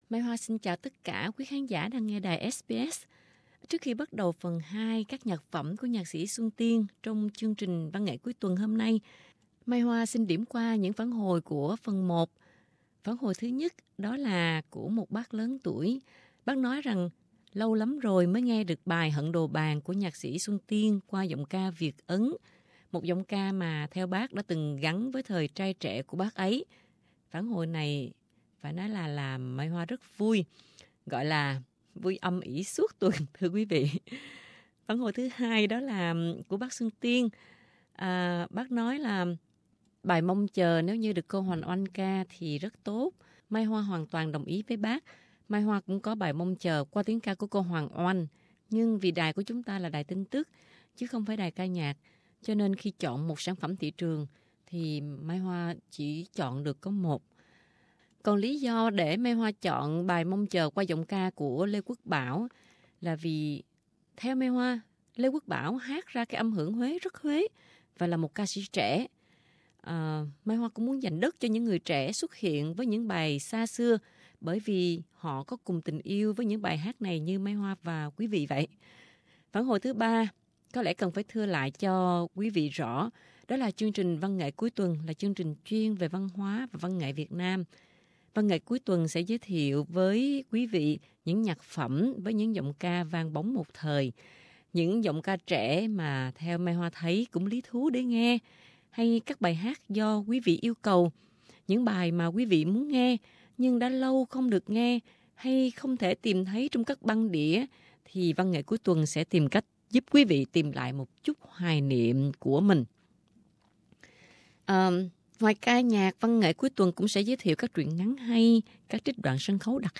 Văn Nghệ Cuối Tuần (1) - Trò chuyện với nhạc sỹ Xuân Tiên